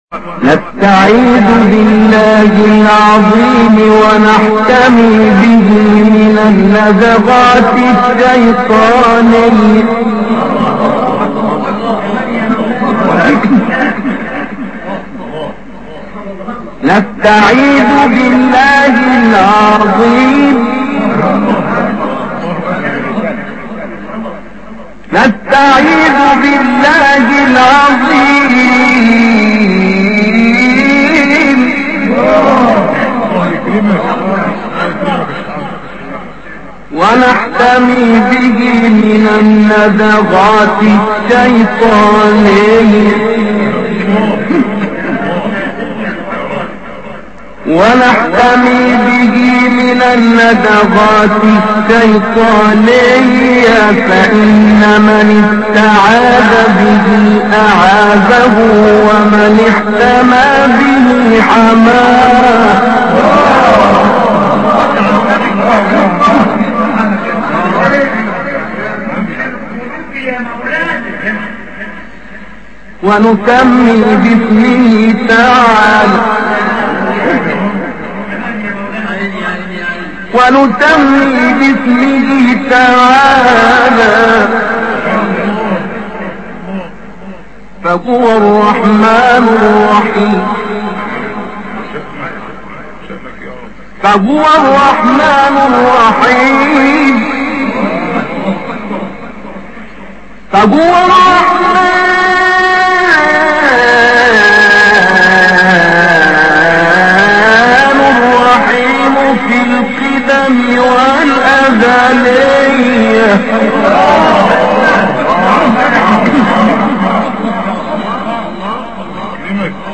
ابتهال زیبای «نستعیذ بالله العظیم» استاد سعید مسلم
صدای زیبا، سبک تلاوت دلنشین و تسلط بر قرائت‌های مختلف، باعث شد آوازه قرائت‌های زیبای وی در همه جا مطرح شود.
ابتهال «نستعیذ بالله العظیم» با صدای استاد روشندل استاد سعید مسلم
ابتهال-الشیخ عنتر سعید مسلم.mp3